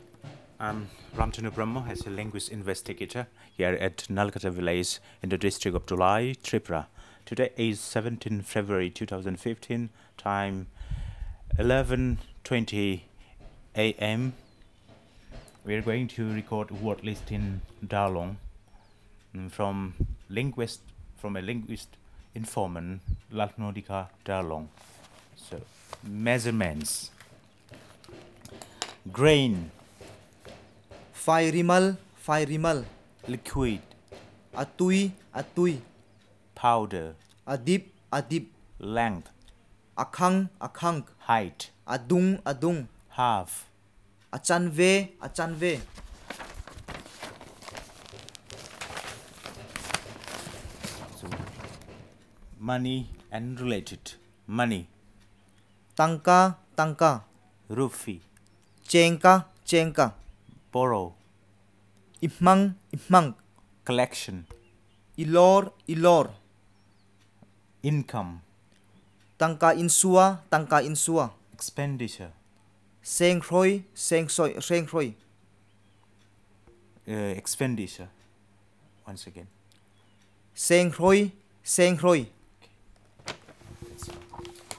Elicitation of words related to measurement and money